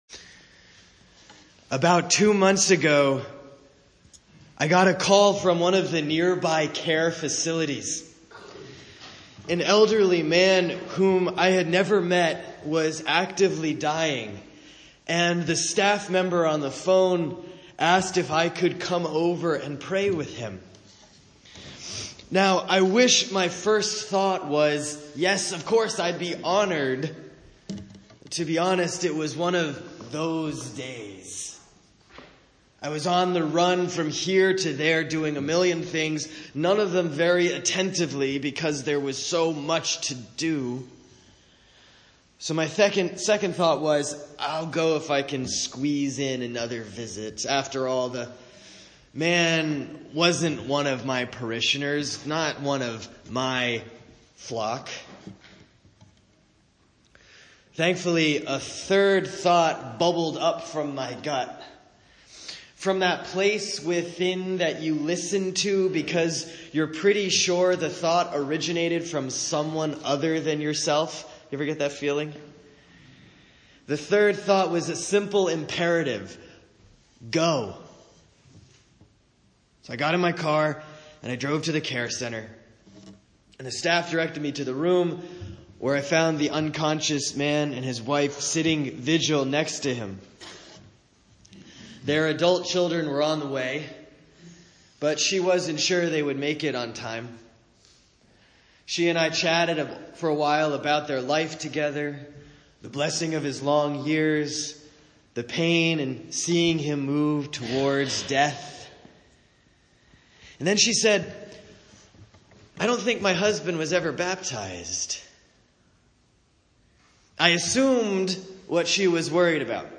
Sermon for Sunday, November 26, 2017 || Reign of Christ, Year A || Ephesians 1:15-23; Matthew 25:31-46